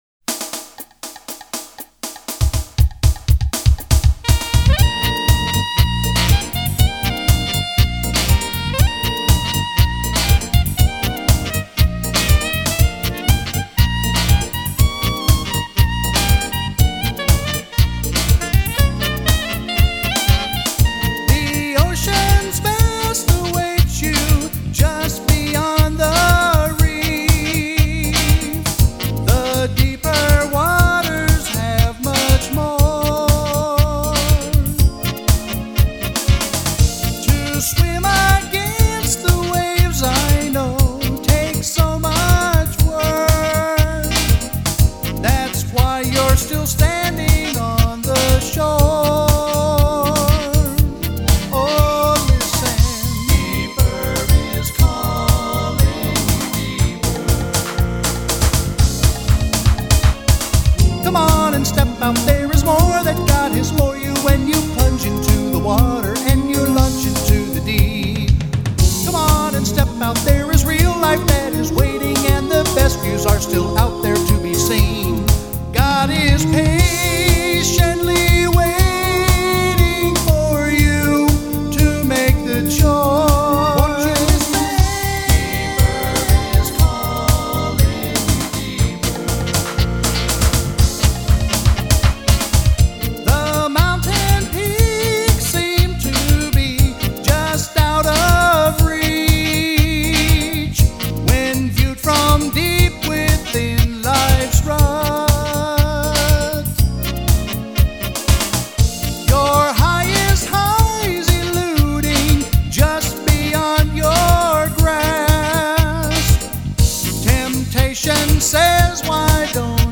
(Hey, that’s a catchy song with a great beat!